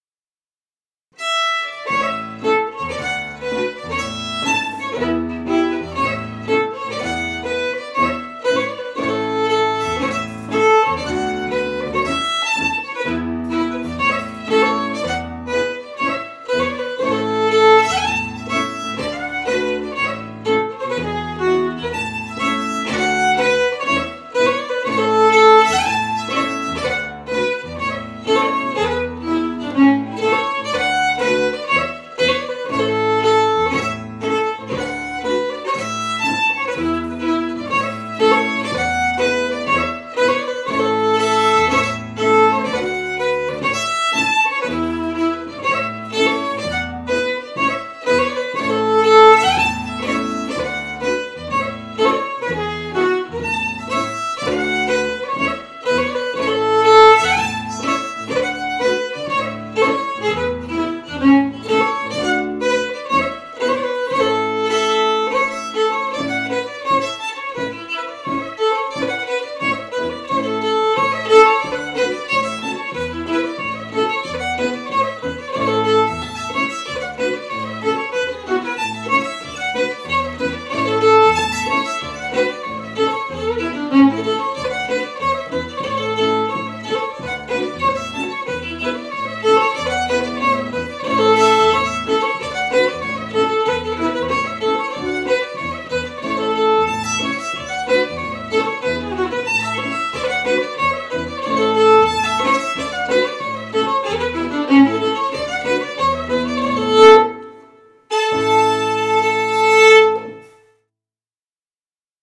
Traditional Scottish Fiddle Music